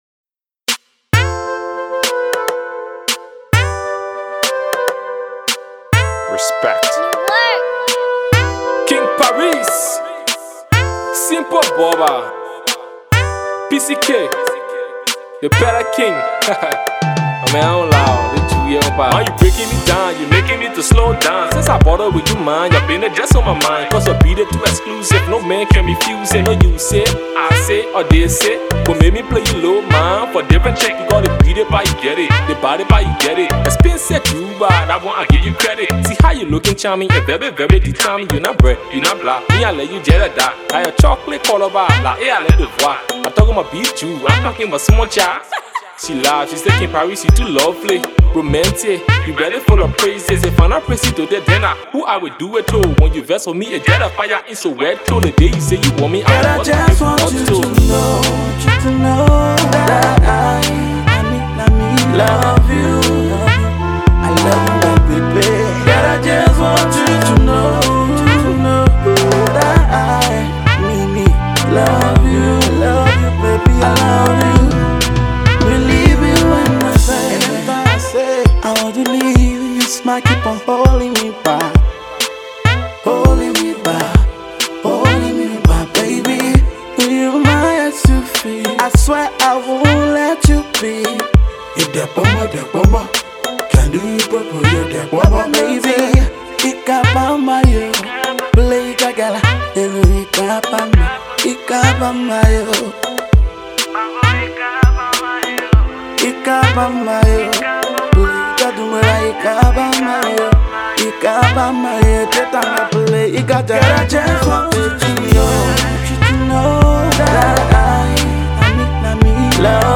Hip-Co